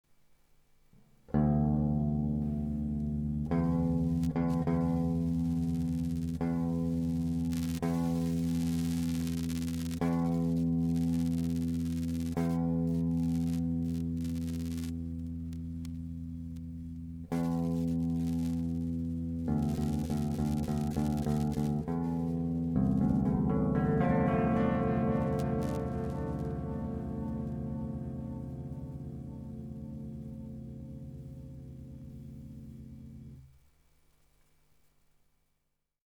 チャリチャノイズは プラスティックメッキ